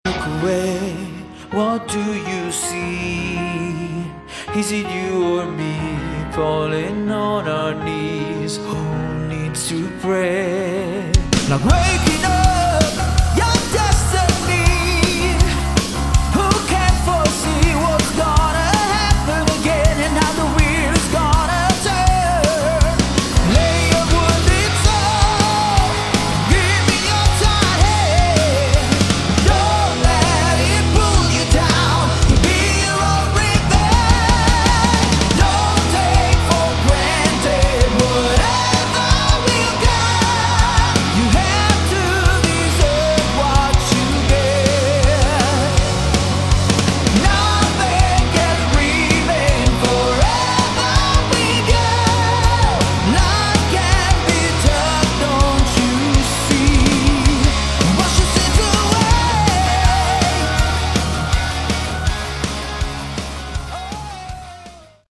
Category: Hard Rock
vocals, keyboards
guitar
bass
drums